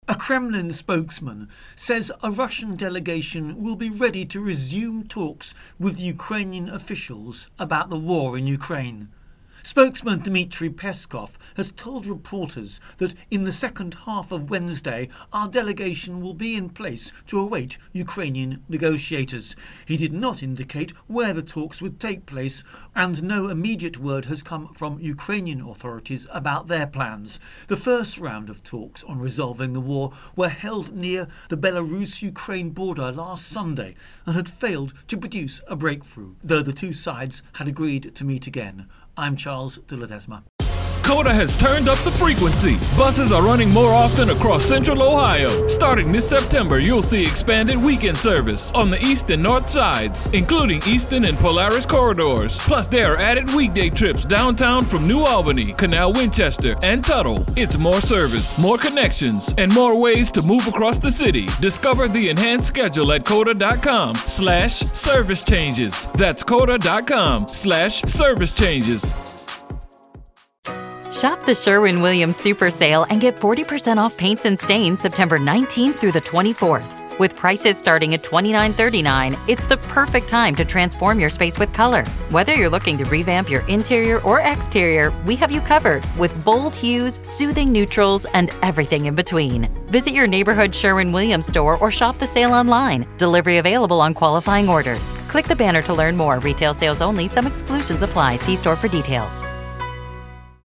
Russia-Ukraine-War-Peskov Intro and Voicer